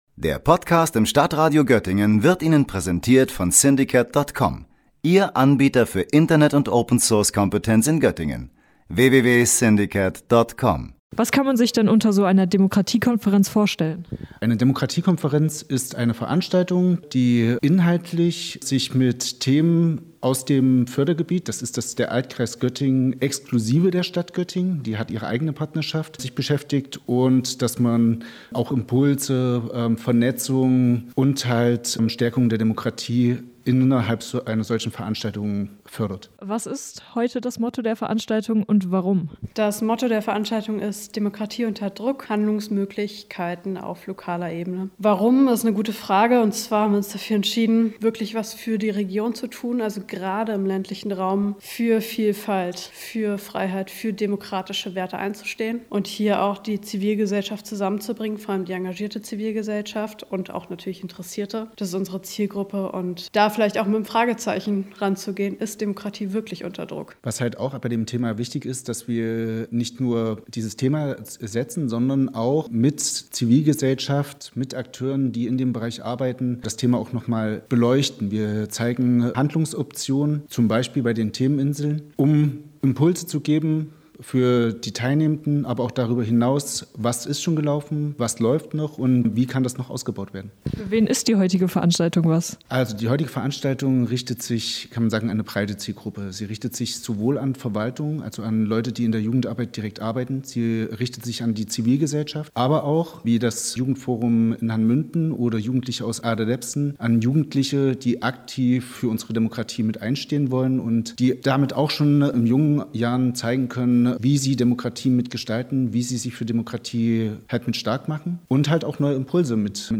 Am vergangenen Mittwoch fand in Rosdorf die Demokratiekonferenz der Partnerschaft für Demokratie im Landkreis Göttingen statt.